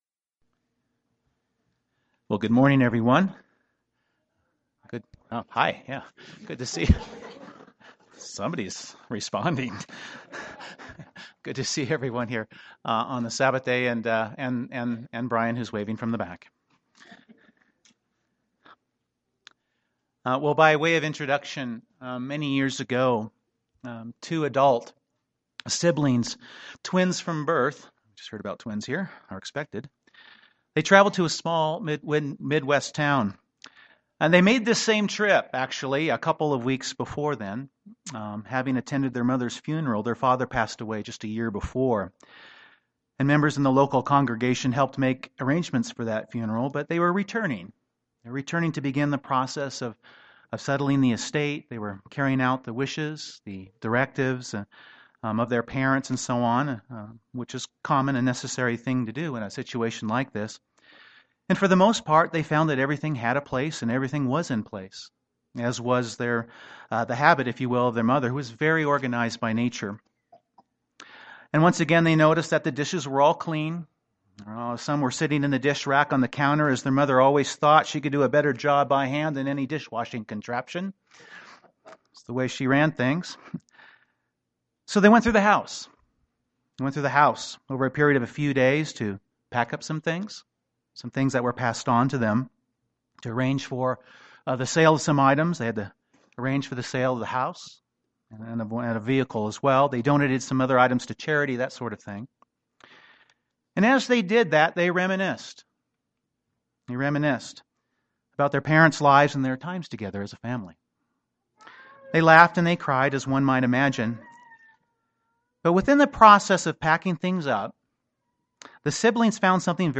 Prayer is an essential part of our relationship with God. This sermon focuses on some of the instructions and guidance God gives regarding prayer with a focus on seeking God’s will (1 John 5:14).